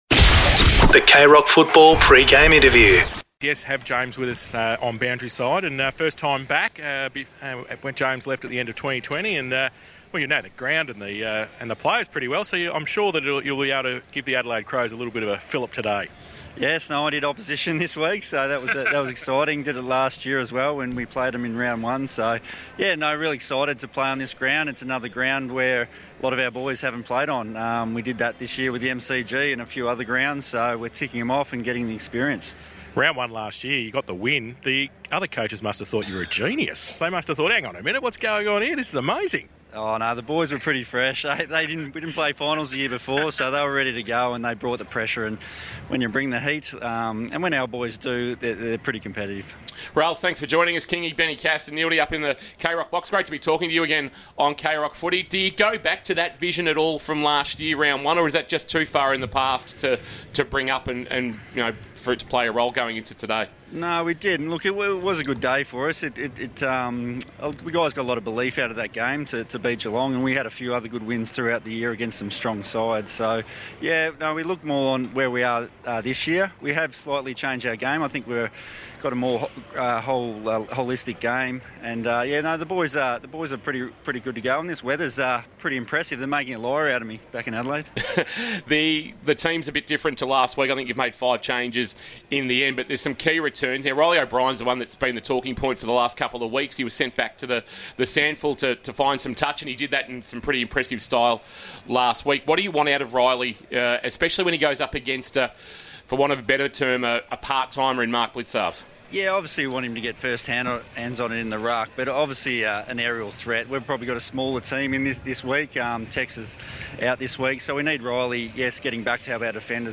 2022 - AFL ROUND 11 - GEELONG vs. ADELAIDE: Pre-match Interview